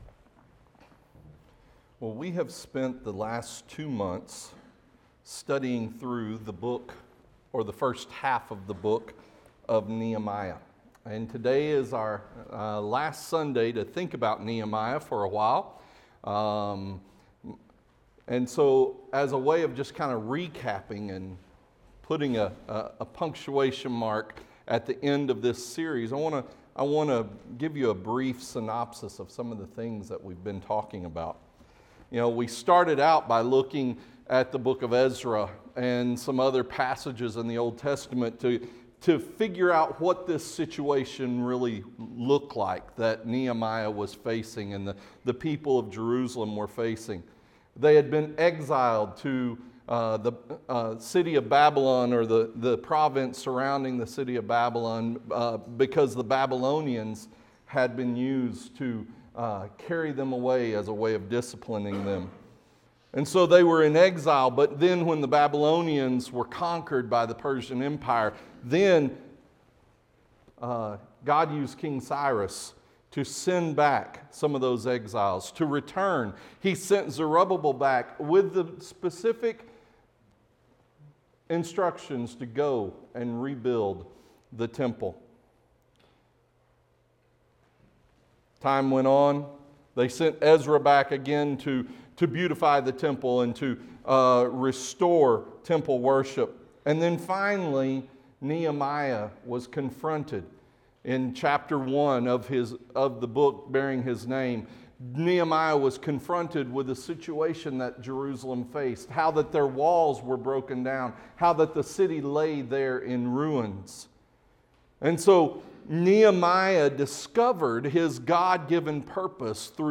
This morning we will hear a stewardship report from The Gideons International on how God has developed this group into the most effective, most comprehensive, and most integrated Bible distribution network the world has ever known.